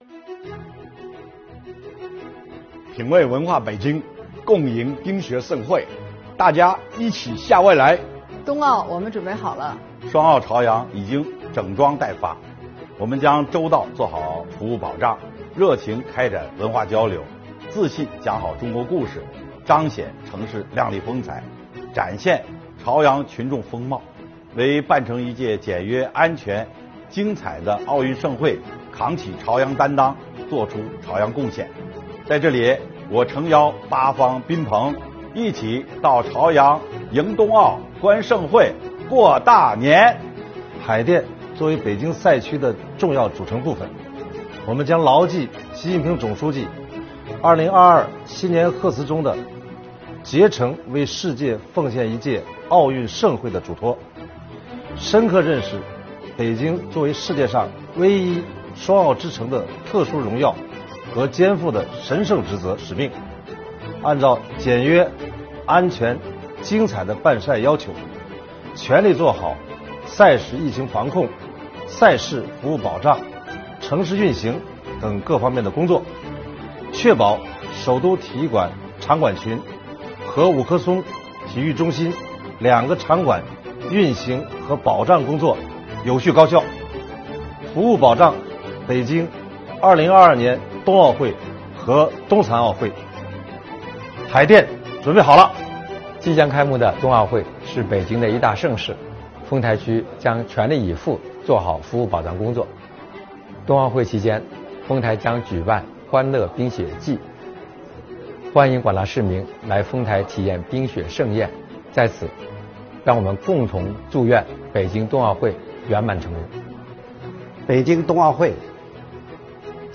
2022年北京冬奥会于今晚8时开幕，北京东城区委书记孙新军，西城区委书记孙军民，朝阳区委书记王灏，海淀区委书记于军，丰台区委书记徐贱云，石景山区委书记常卫，门头沟区委书记金晖，房山区委书记陈清，通州区委书记赵磊，顺义区委书记高朋，昌平区委书记甘靖中，大兴区委书记王有国，怀柔区委书记郭延红，平谷区委书记唐海龙，密云区委书记余卫国，延庆区委书记穆鹏，北京经开区工委书记王少峰，市财政局局长吴素芳，通过北京日报客户端，送上冬奥寄语！